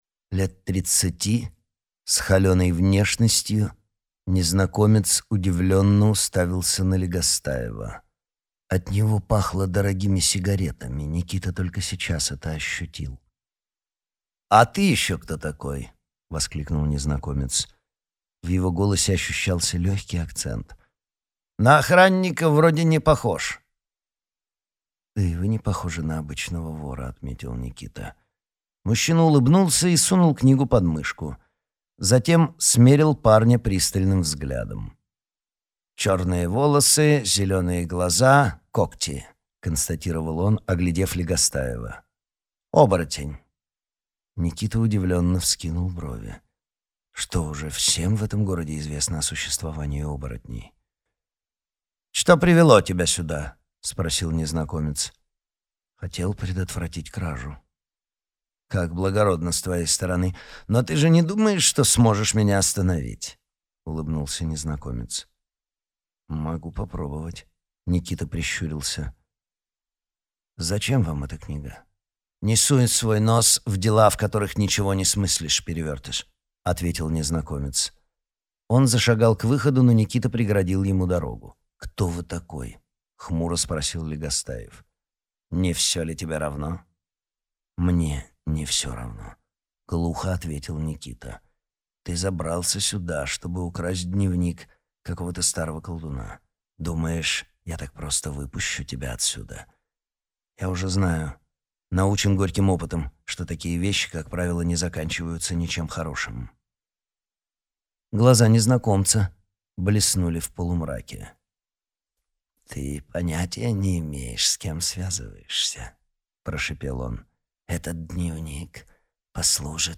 Аудиокнига Пардус. Присягнувшие тьме | Библиотека аудиокниг